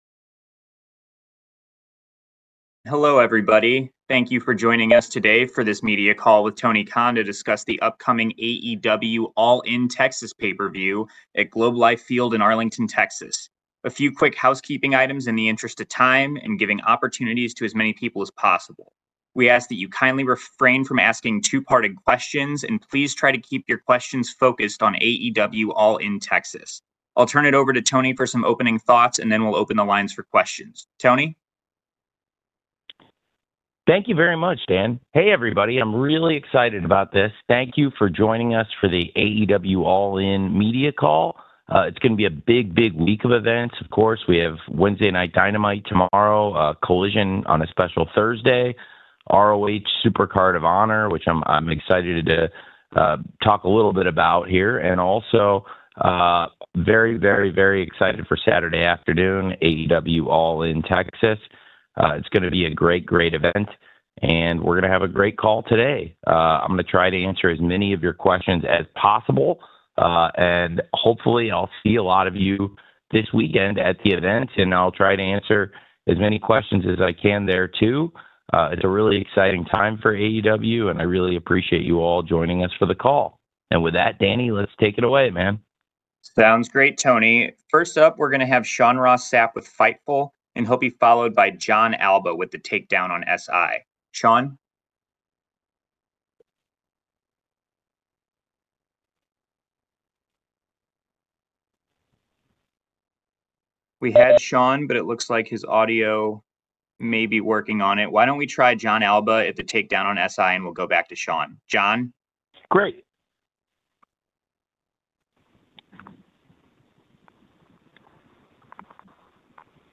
Tony Khan's All In Media Call: Big Week for AEW & Title Unification Clarification!